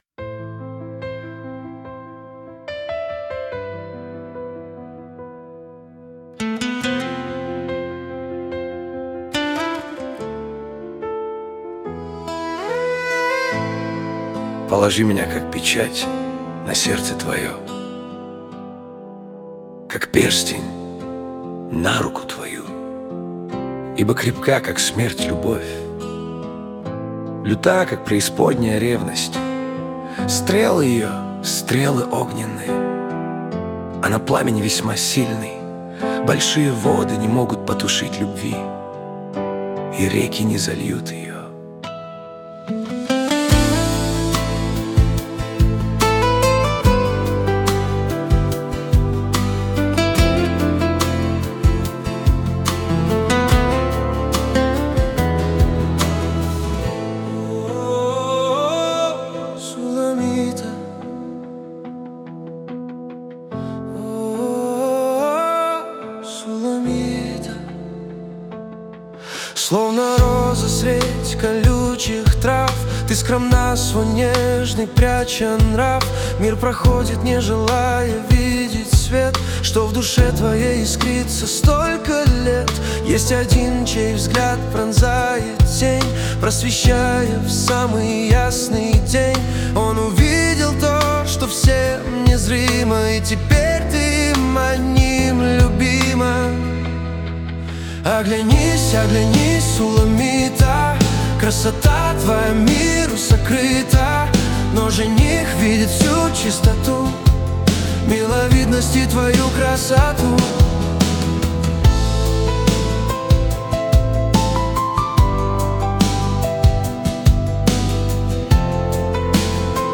песня ai
240 просмотров 756 прослушиваний 78 скачиваний BPM: 72